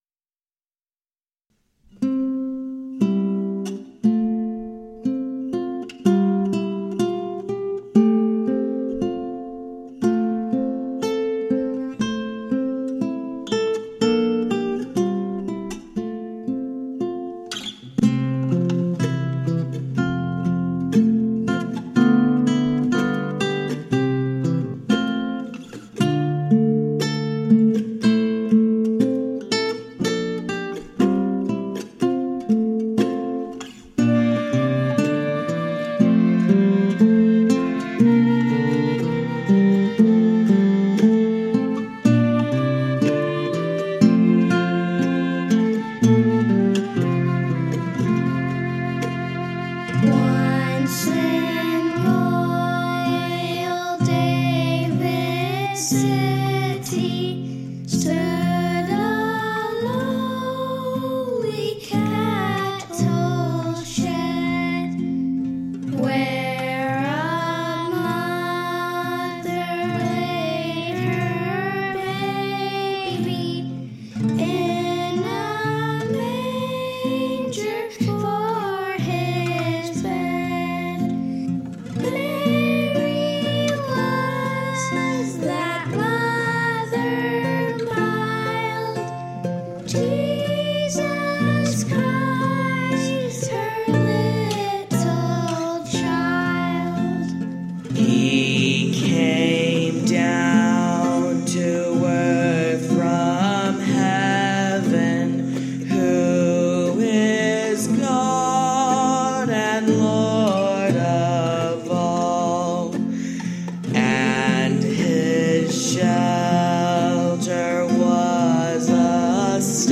guitar, mandolin, banjo, vocals
clarinet, vocals